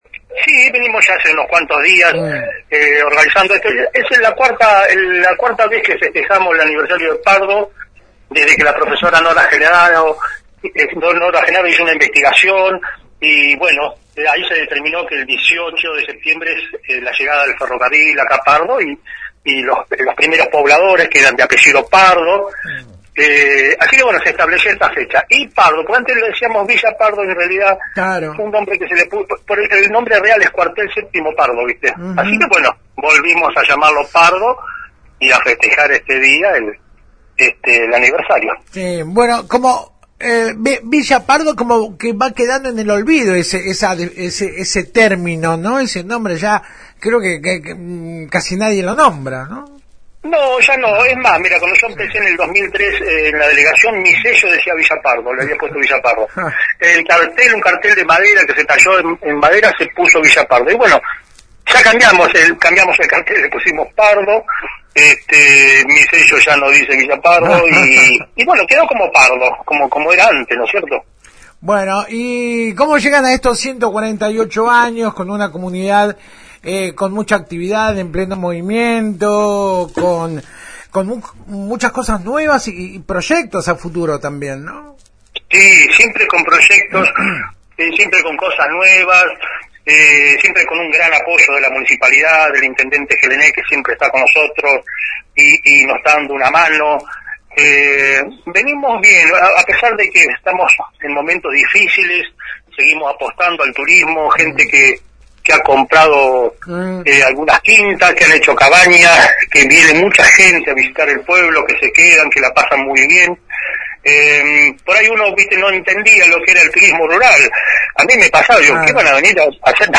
«Pese al difícil momento nos encontramos muy bien y creciendo de a poco con una apuesta fuerte al turismo rural y demás proyectos que hacen a nuestro querido paraje», sostuvo ésta mañana Lisandro Paggi, delegado municipal, a FM Alpha.